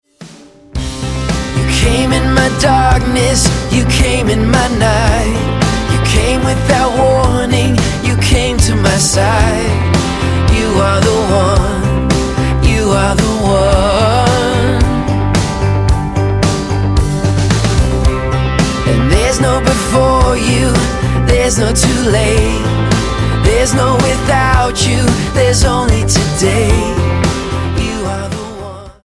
acoustic pop
Style: Pop